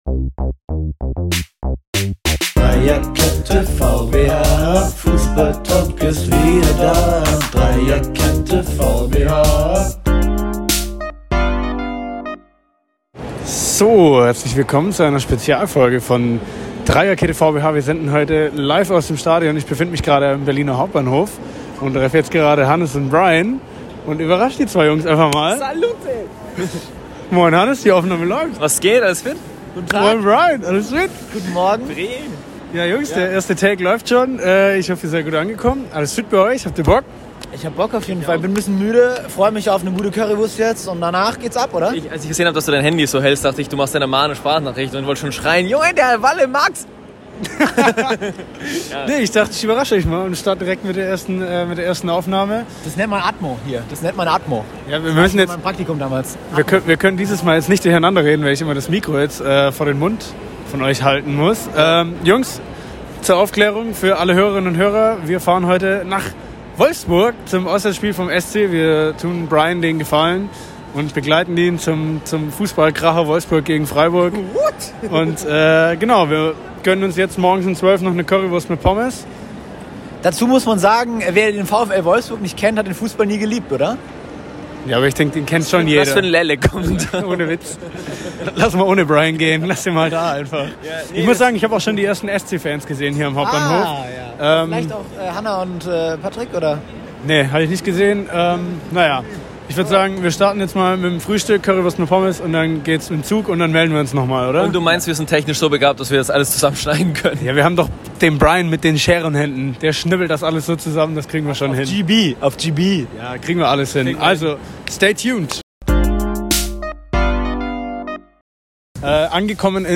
Beschreibung vor 3 Jahren Nach knapp 10 Wochen Pause ist die Bundesliga endlich zurück! Und natürlich haben wir, eure Lieblingsdreierkonstellation, den Auftakt des Ligabetriebes nicht verpennt und sind zu diesem Anlass nach Wolfsburg gefahren.